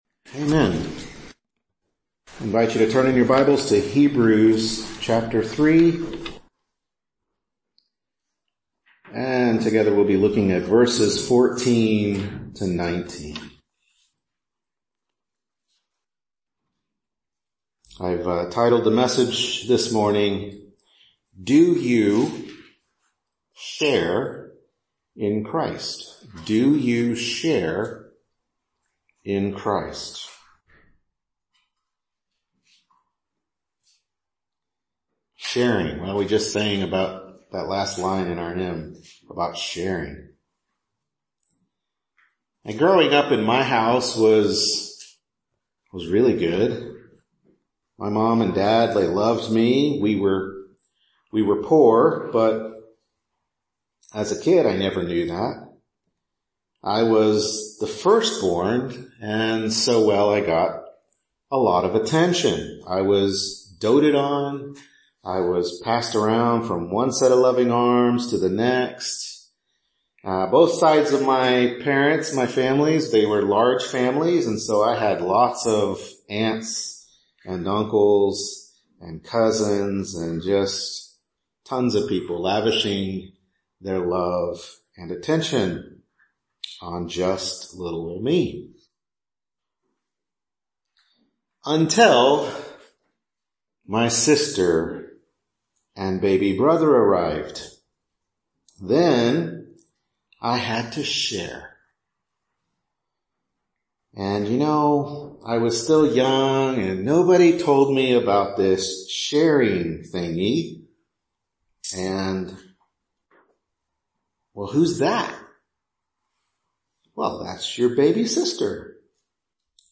Hebrews 3:14-19 Service Type: Morning Worship Service Hebrews 3:14-19 Do You Share in Christ?